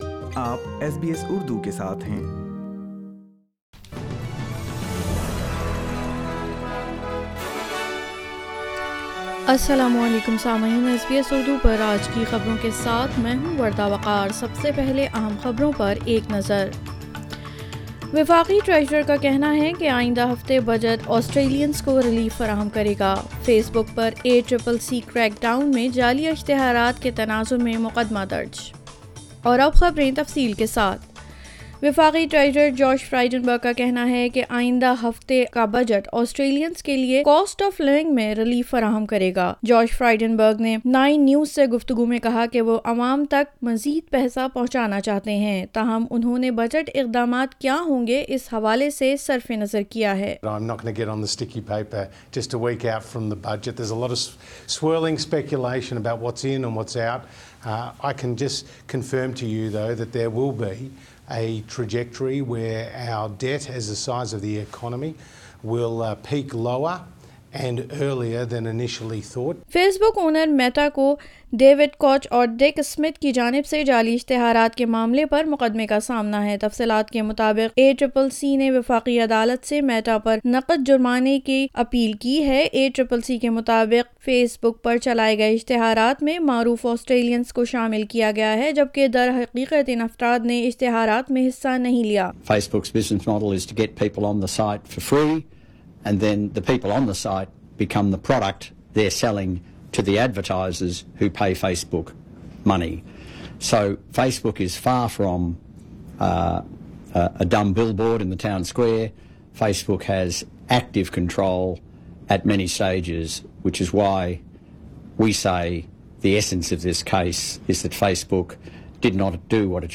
SBS Urdu News 18 March 2022